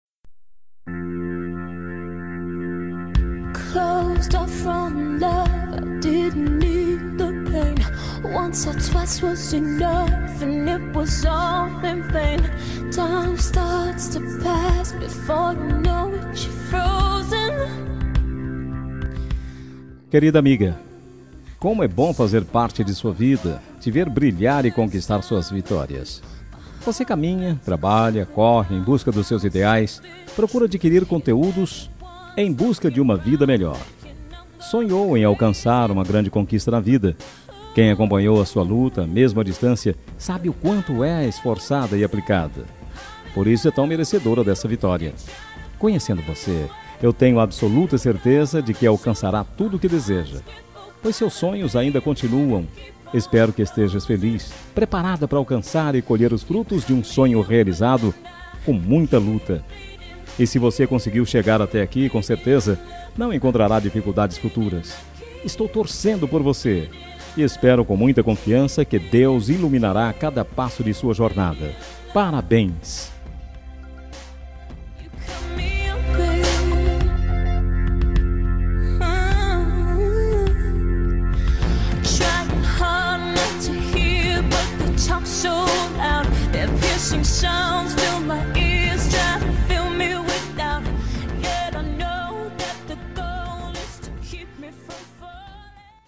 Telemensagem Aniversário de Amiga – Voz Masculina – Cód: 202061